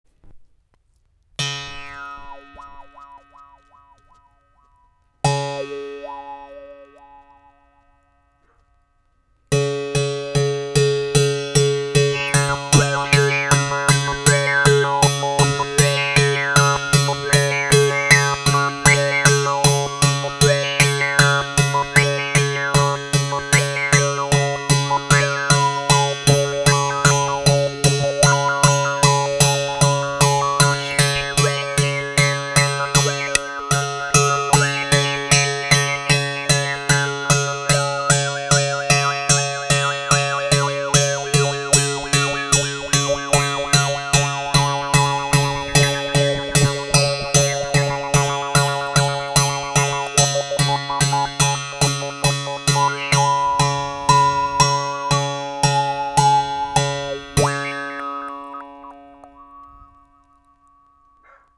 ARC EN BOUCHE 1 CORDE
Les sons proposés ici sont réalisés sans effet.
La baguette sera utilisée pour percuter la corde.